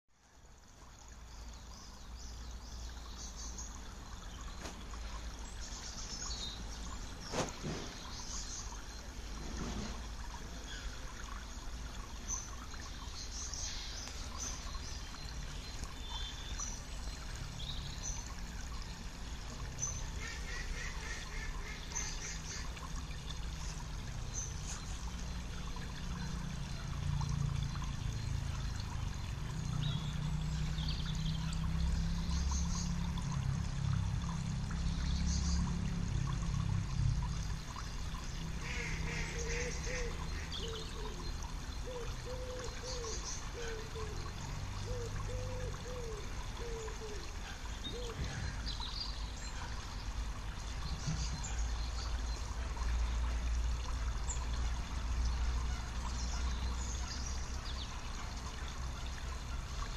I have attached an audio file, of water trickling over a weir, using the paperclip then clicking in the musical note option.